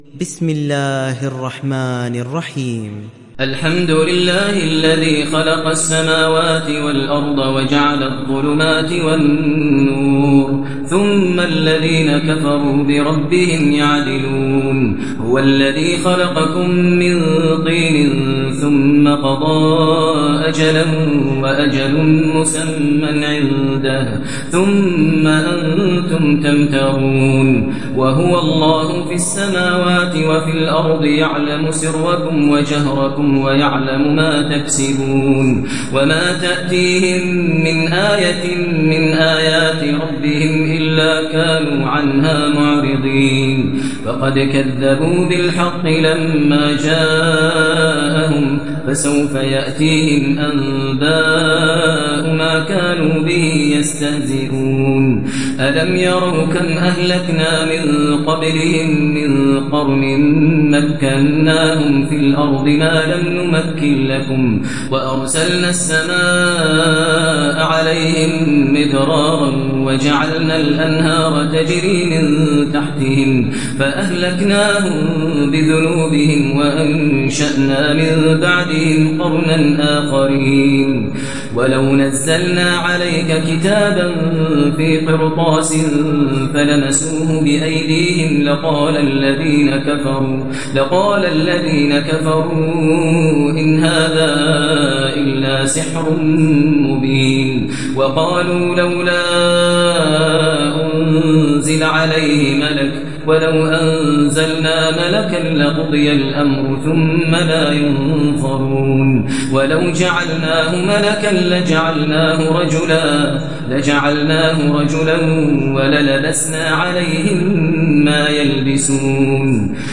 Sourate Al Anaam Télécharger mp3 Maher Al Muaiqly Riwayat Hafs an Assim, Téléchargez le Coran et écoutez les liens directs complets mp3